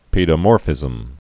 (pēdə-môrfĭzəm)